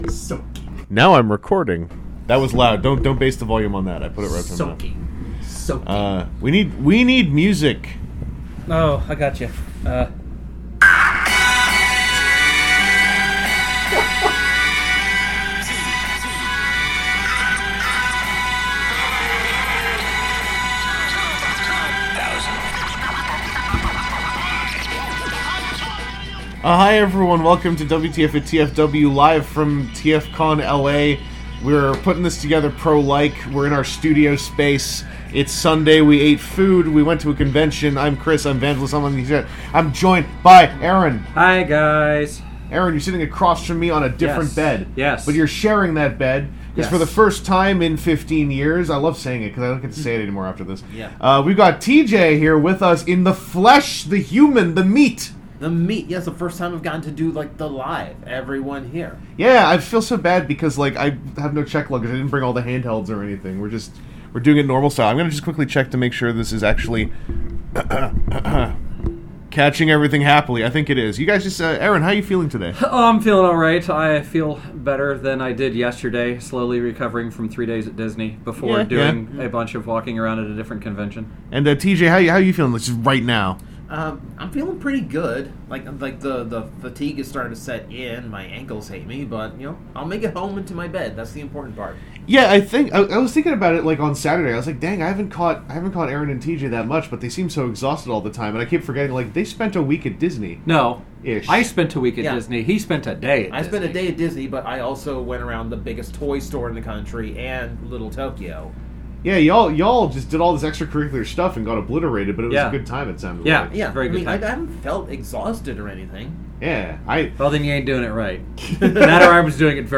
LIVE on location from the surprisingly rainy streets of Burbank, the WTF @ TFW crew is here for a special TFCON LA 2025 episode of the podcast!